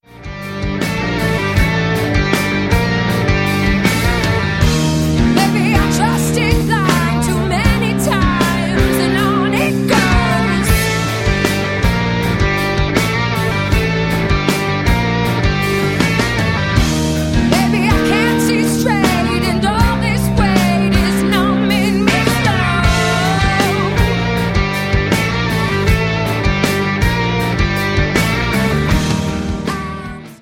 • Sachgebiet: Rock